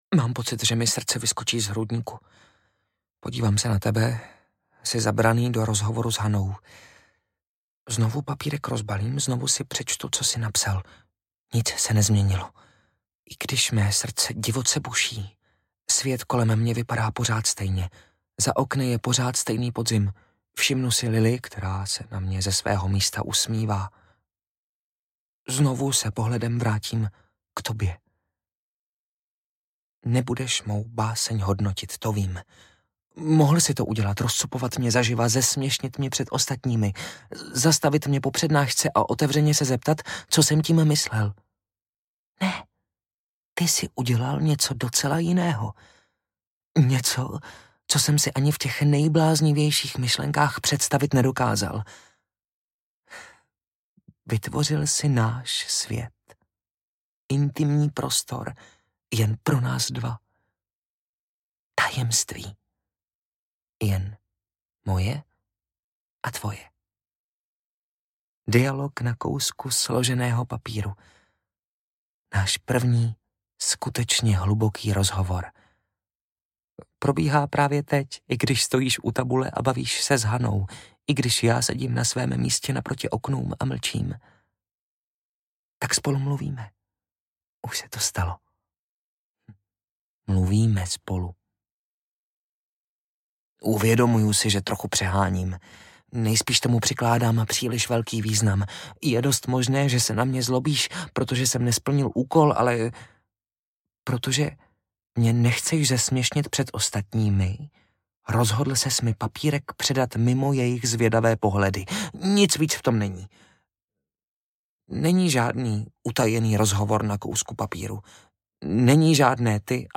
Počkej na moře audiokniha
Ukázka z knihy
Vyrobilo studio Soundguru.